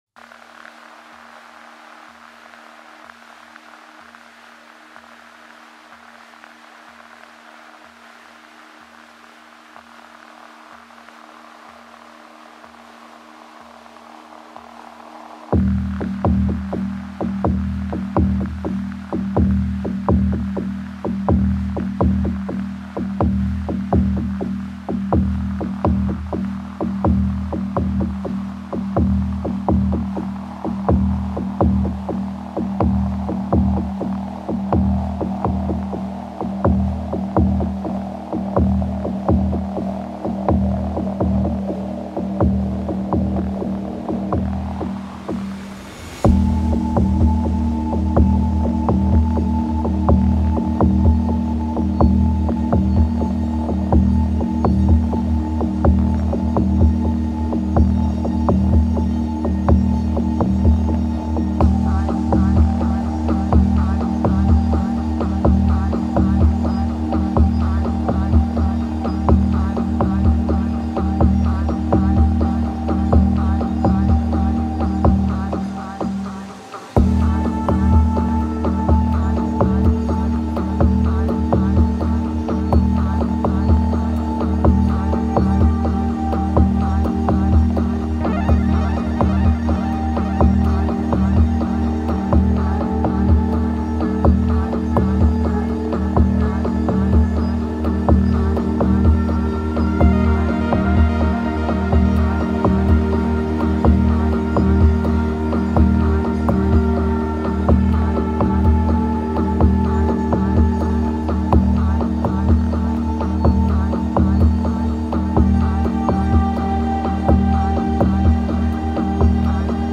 Tags2020s 2023 Canada Electronic